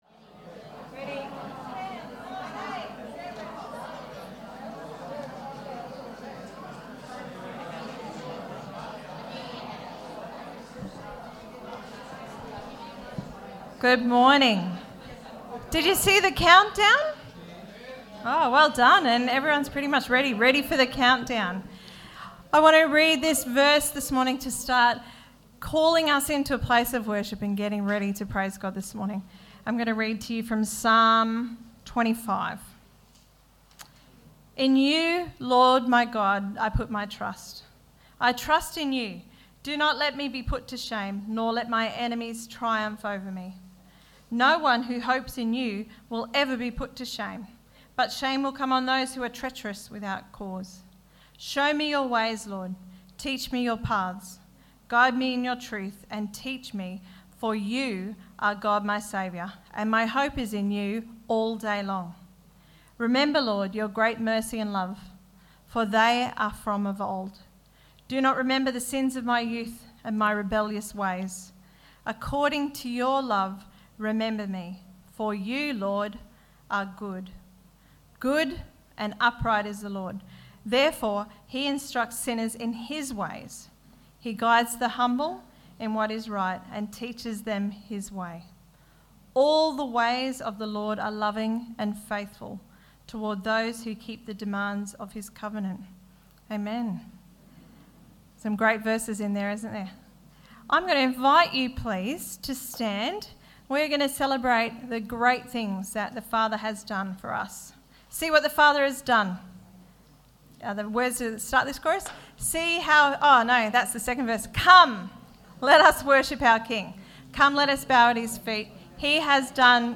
Reading is from Galatians 5:16-25. Some Audio content has been remove due to Copyright requirements